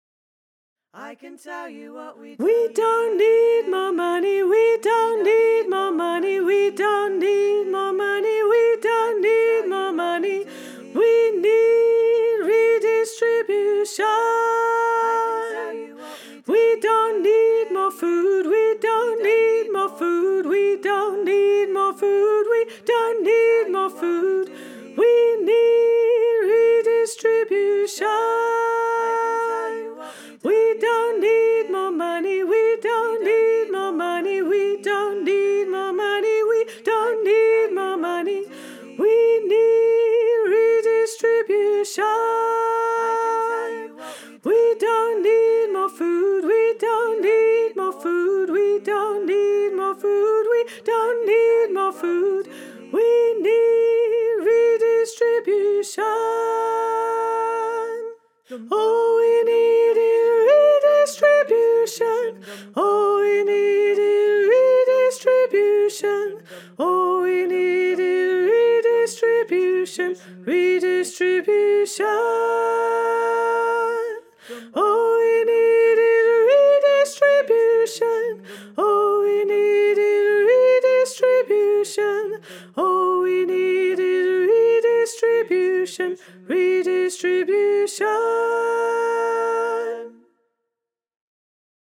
Sop:
redistribution_learning-tracks_high.wav